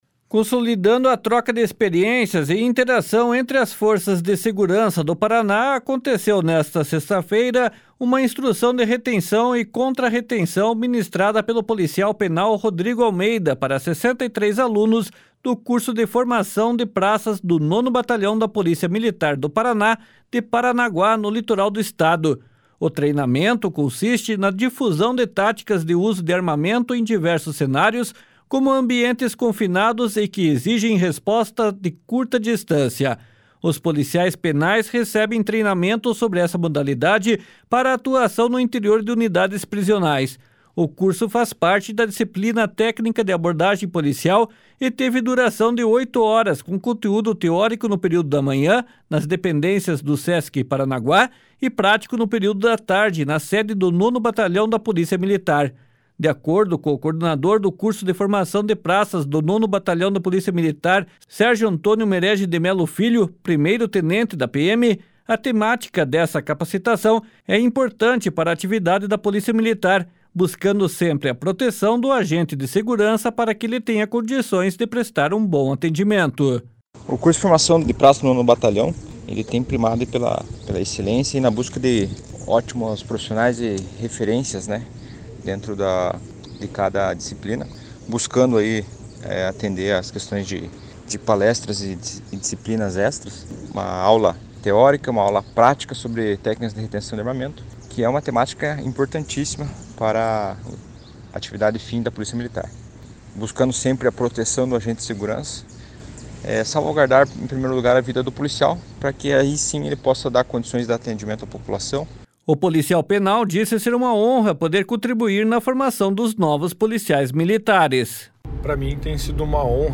O policial penal disse ser uma honra poder contribuir na formação dos novos policiais militares.